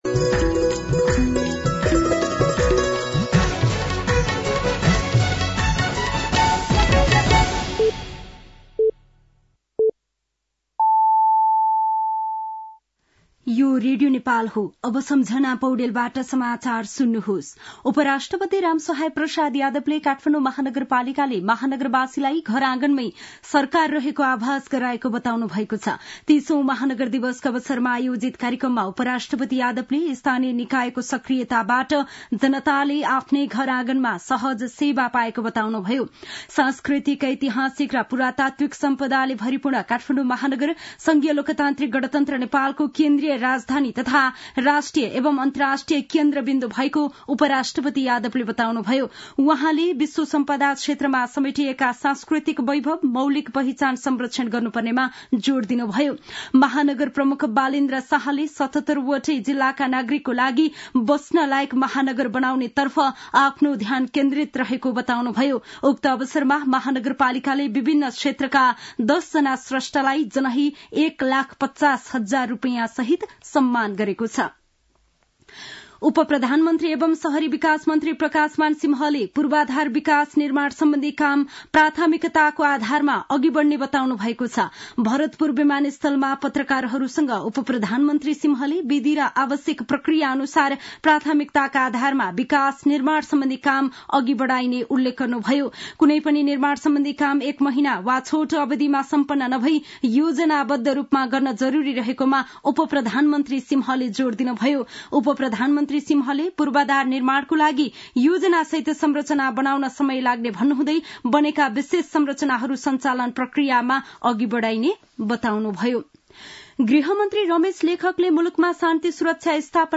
साँझ ५ बजेको नेपाली समाचार : ३० मंसिर , २०८१